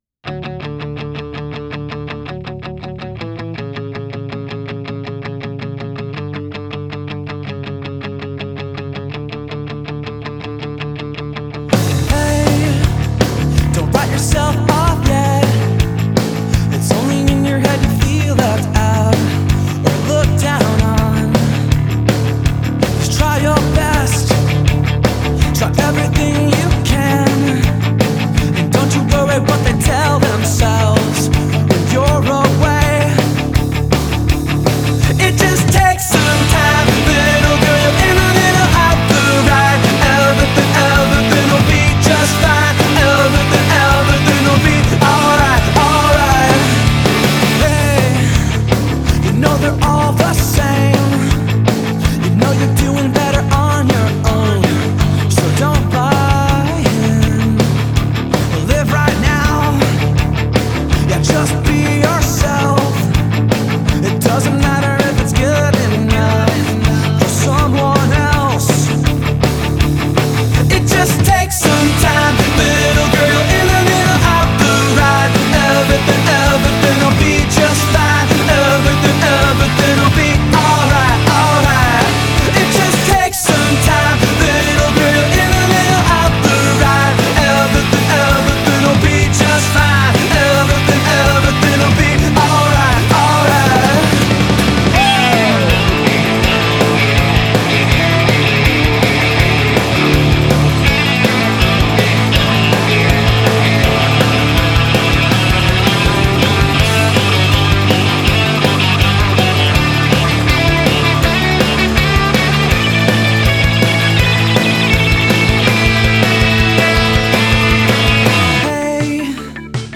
Genre: Emo.